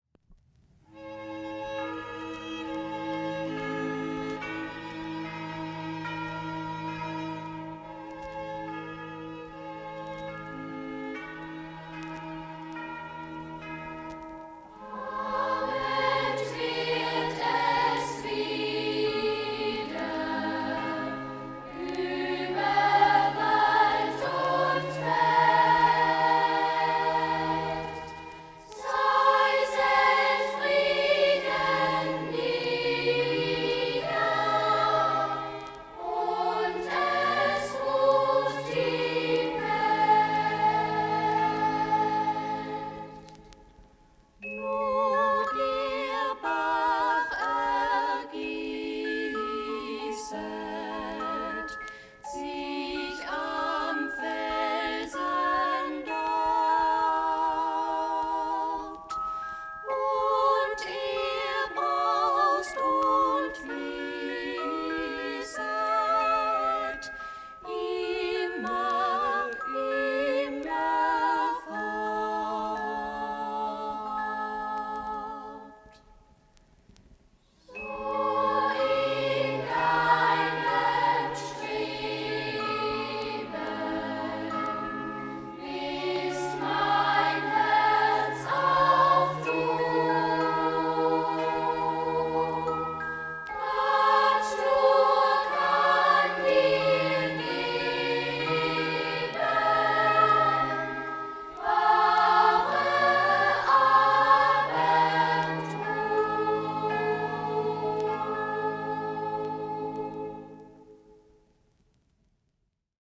gefühlvollen Melodie
Abend- und Schlaflieder